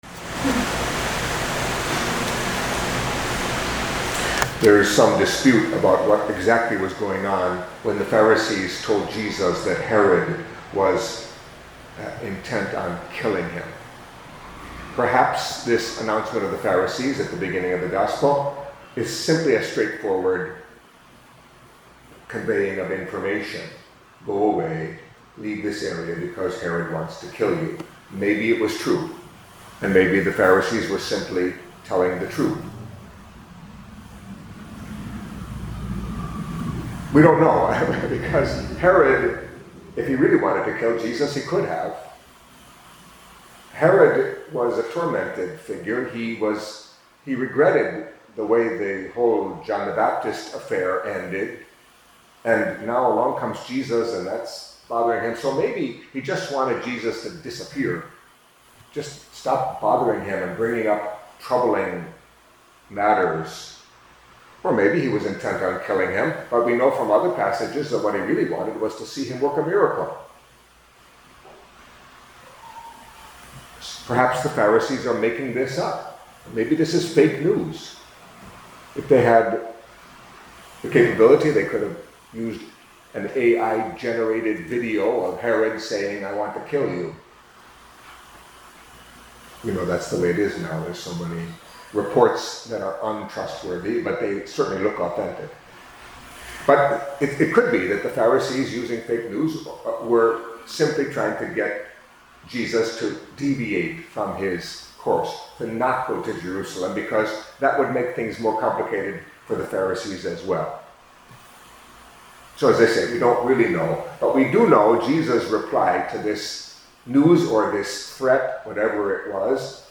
Catholic Mass homily for Thursday of the Thirtieth Week in Ordinary Time